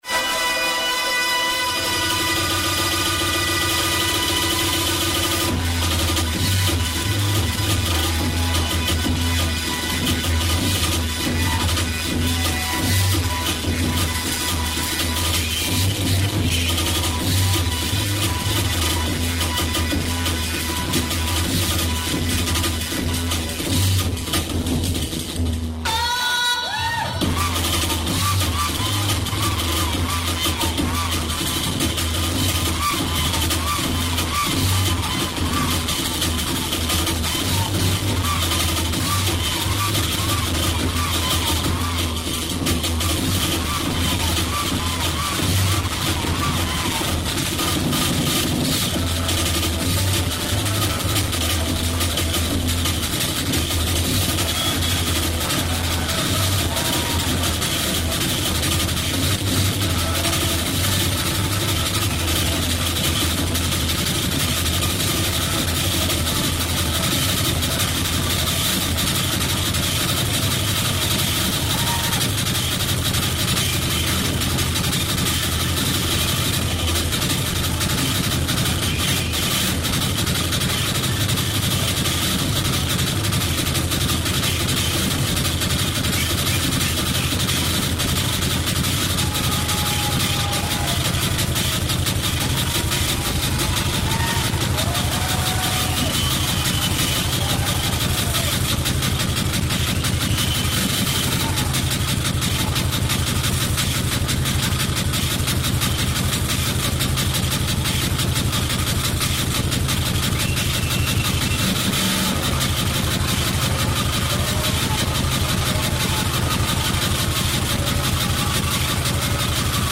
location Melbourne, Australia